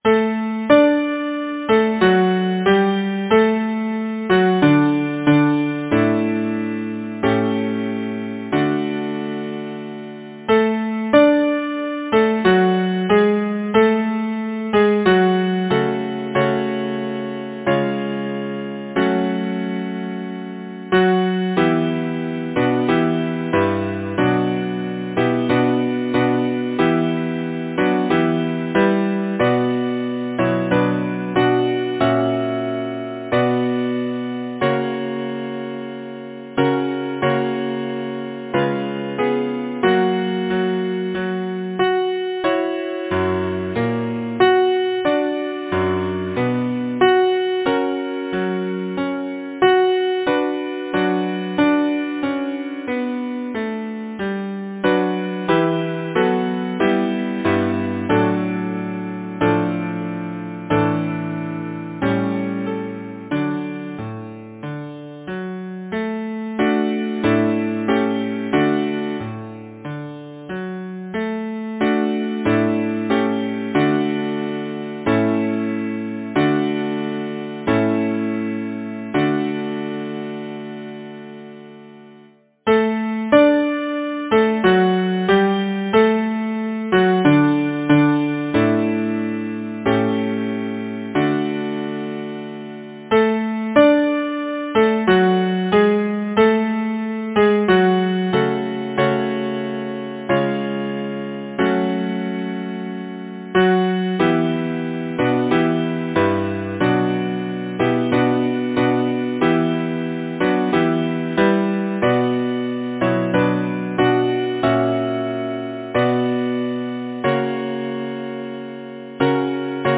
Composer: Paul David Lyricist: Edward Thring Number of voices: 4vv Voicing: SATB Genre: Secular, Partsong
Language: English Instruments: A cappella